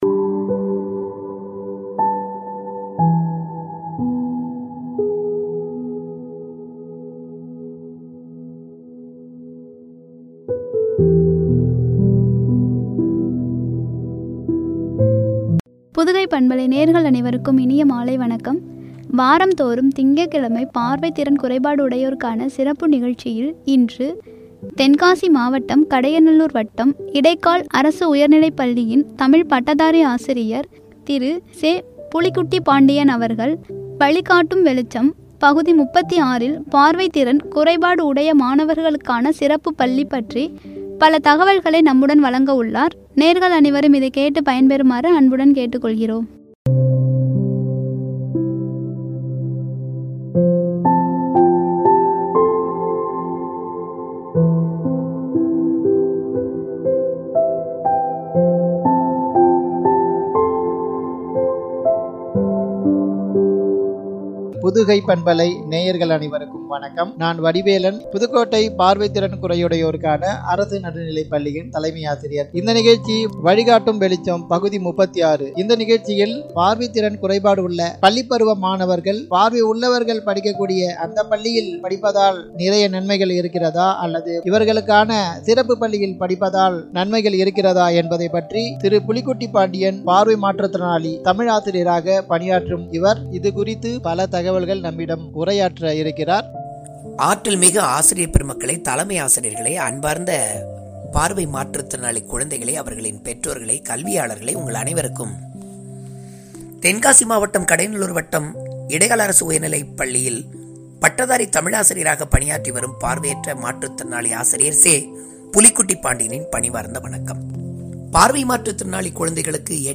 உரை.